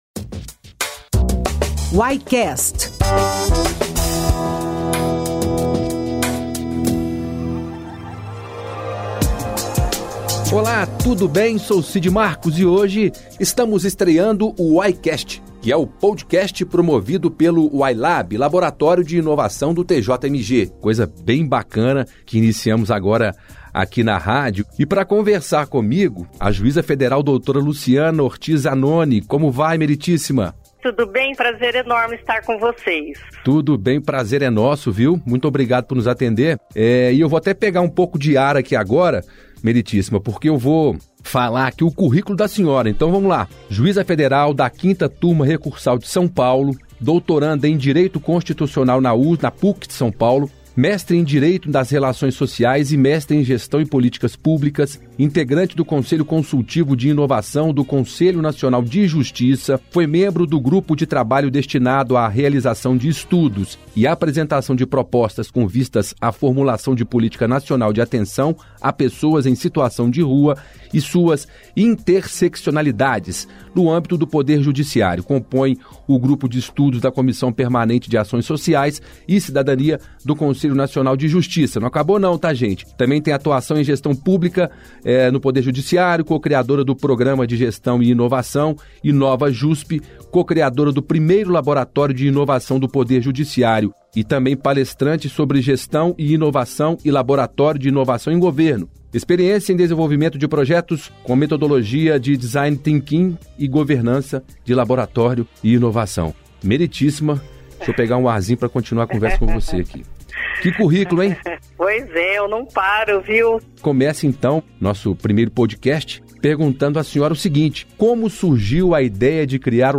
Estreia com entrevista da juíza federal Luciana Ortiz Zanoni
O episódio de inauguração foi uma entrevista com a juíza federal Luciana Ortiz Zanoni, sobre sua experiência com inovação na gestão pública.